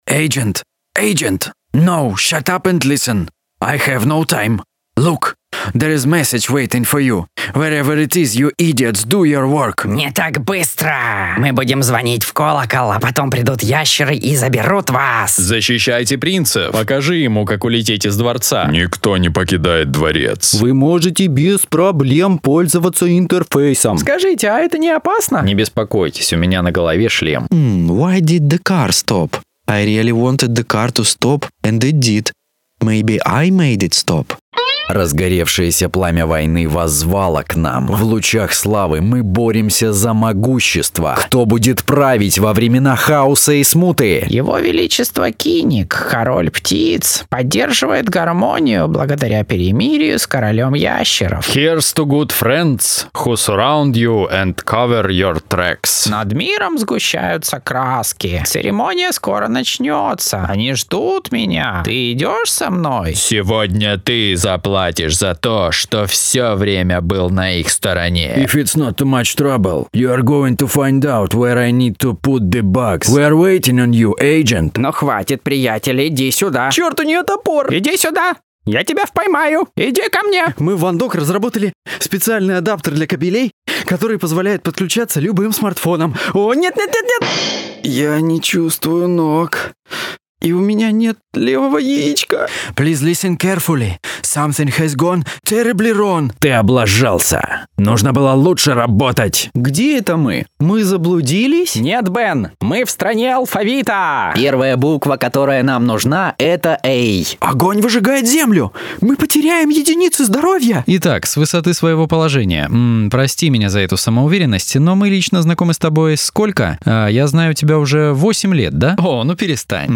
Муж, Другая
Rode NT1 Rode NTG2 ISK BM-800 Октава МК-012 ART Voice Channel Presonus StudioLive 16.0.2 ESI Juli@